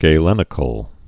(gā-lĕnĭ-kəl, gə-)